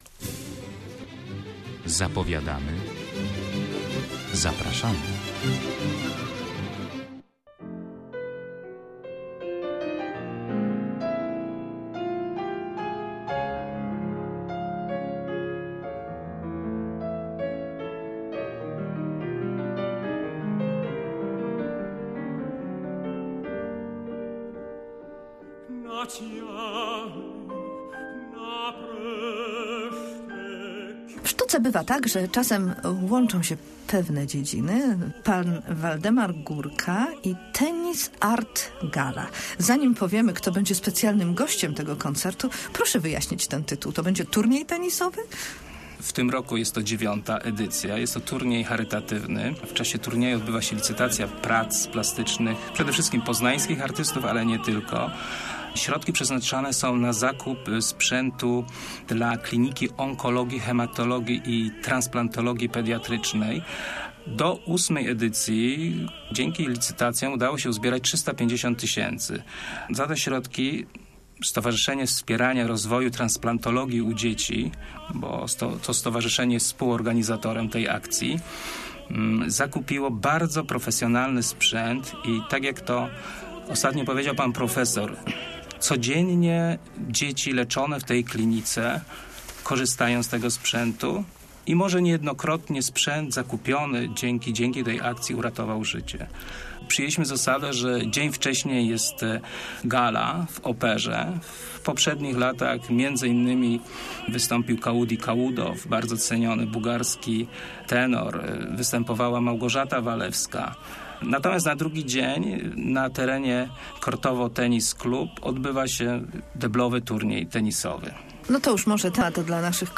Pavla Breslika, jednego z najbardziej uznanych słowackich tenorów, usłyszeliśmy w piątek w czasie koncertu towarzyszącego IX edycji Tennis Art Club.